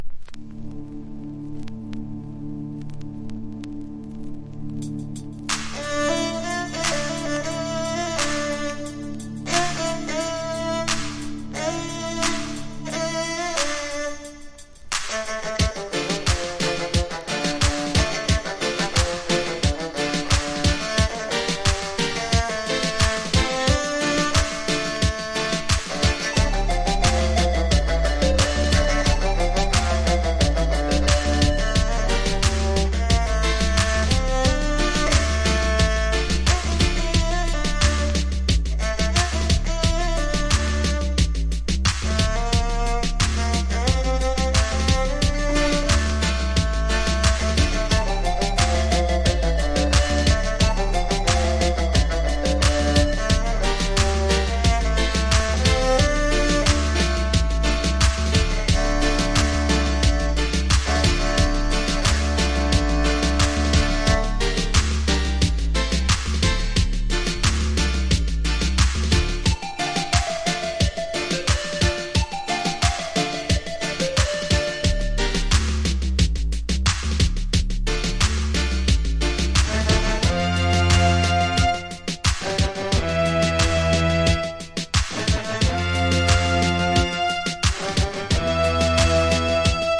• REGGAE-SKA
形式 : 7inch / 型番 : / 原産国 : JAM